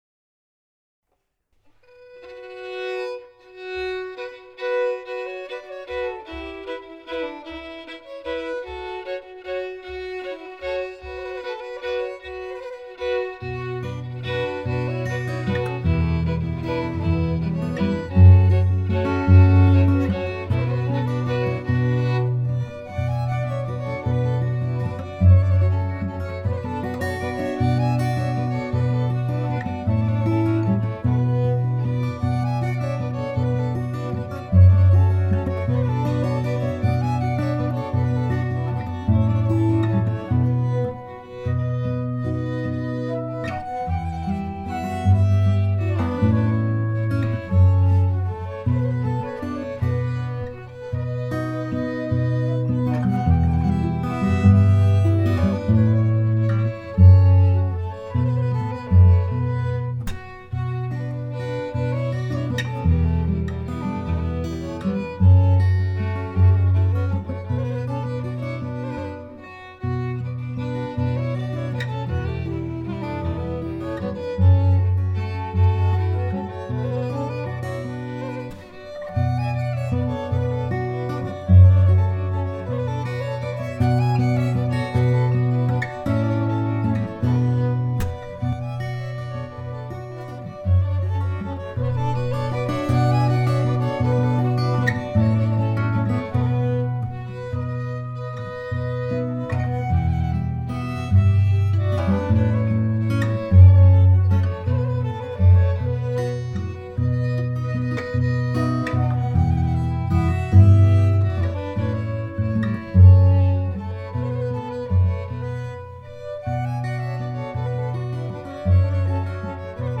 H-mollvalsen (ackord).mp3   3.13 MB
H-mollvalsen-ackord.mp3